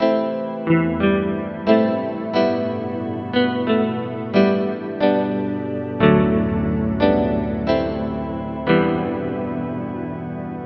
操作 Illenium混音下降的人声合成器 DRY
这是没有经过效果处理的干循环。
标签： 90 bpm Chill Out Loops Synth Loops 1.79 MB wav Key : F
声道立体声